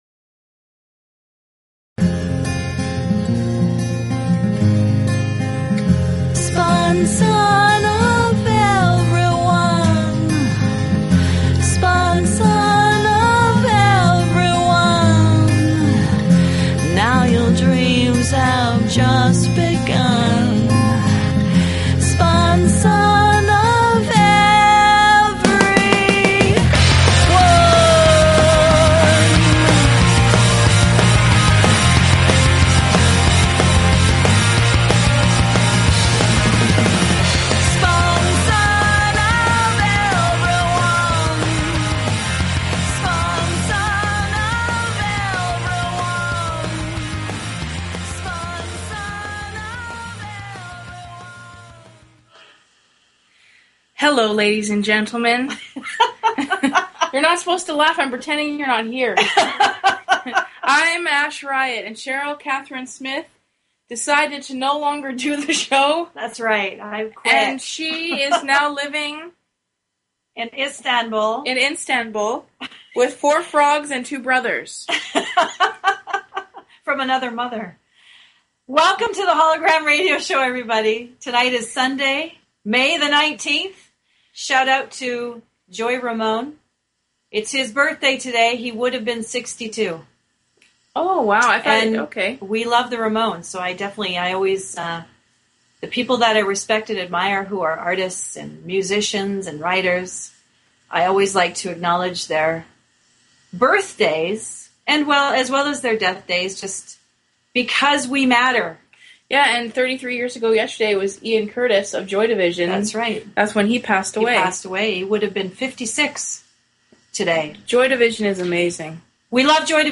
Talk Show Episode, Audio Podcast, Hollow-Gram_Radio_Show and Courtesy of BBS Radio on , show guests , about , categorized as
They offer a unique radio show where they share their music, their sharp wit, and their visions for This planet.